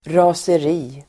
Uttal: [ra:ser'i:]